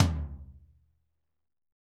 TOM P C L0YR.wav